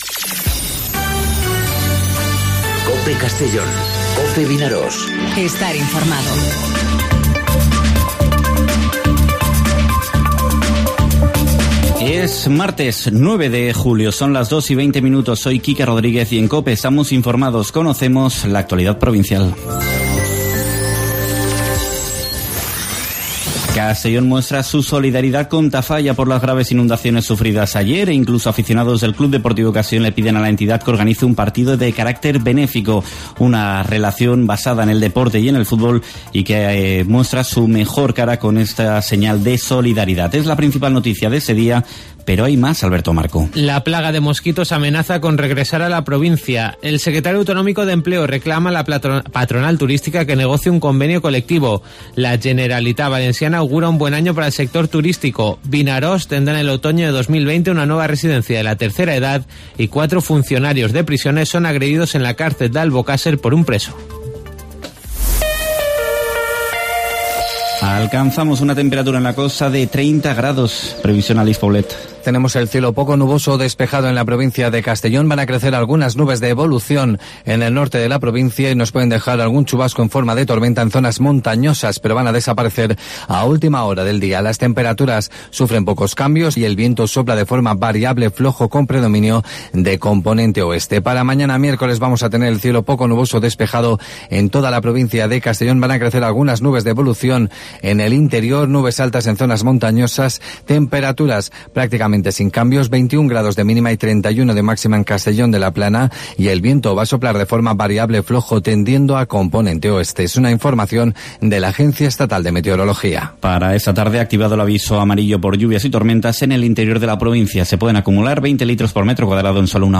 Informativo 'Mediodía COPE' en Castellón (09/07/2019)